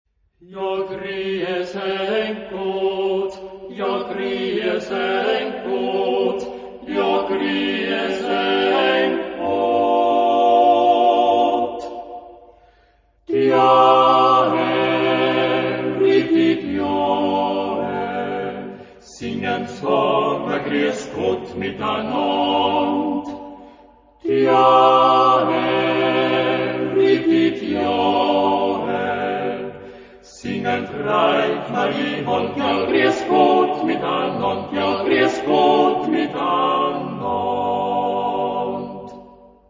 Genre-Style-Forme : Profane ; Populaire
Caractère de la pièce : expressif
Type de choeur : TTBB  (4 voix égales d'hommes )
Tonalité : fa majeur